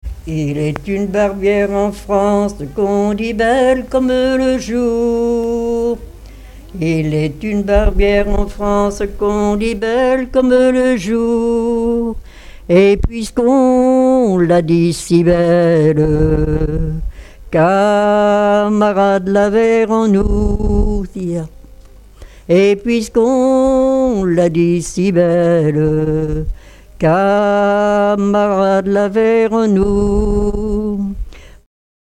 Enquête dans les Résidences de personnes âgées du Havre
Témoignages et chansons populaires
Pièce musicale inédite